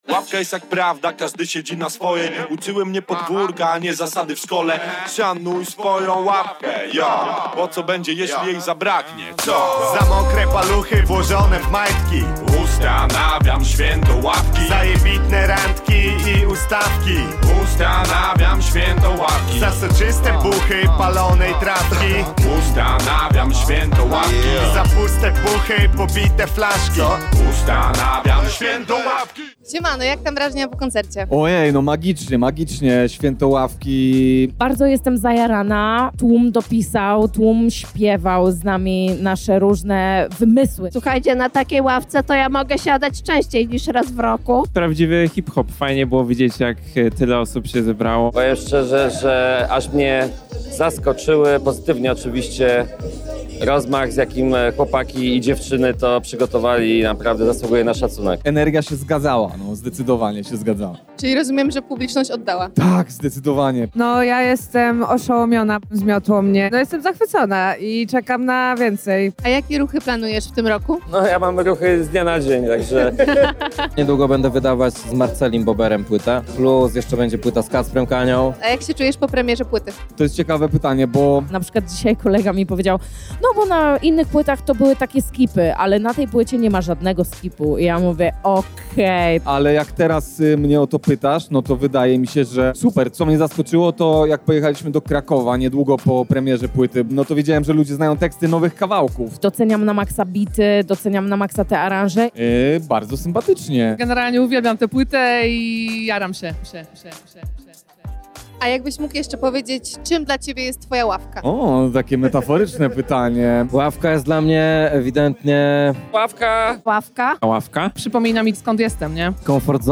Święto Ławki to już obowiązkowe wydarzenie dla ludzi hip-hopu i ogromnie cieszymy się, że mieliśmy możliwość nie tylko tego doświadczyć, ale również porozmawiać z artystami.
Sonda-Swieto-Lawki-2025.mp3